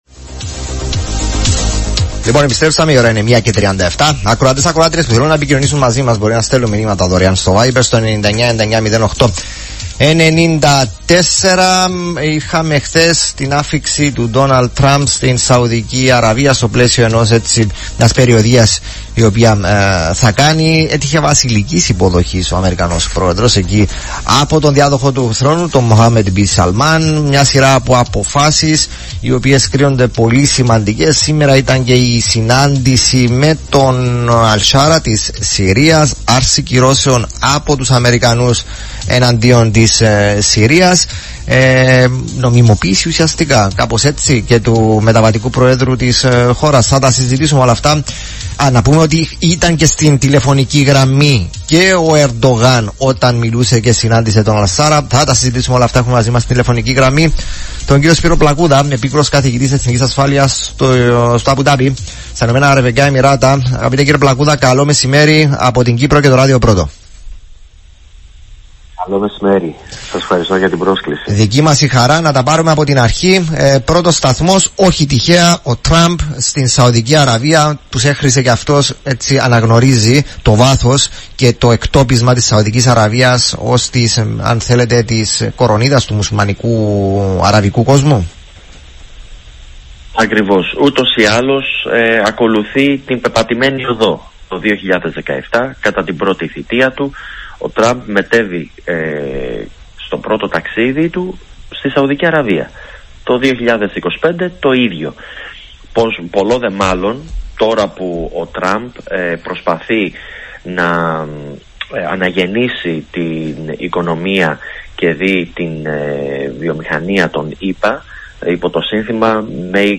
Ηχητικό απόσπασμα από τη συζήτηση στην εκπομπή "Ράδιο Πρώτο" της Κύπρου περί της περι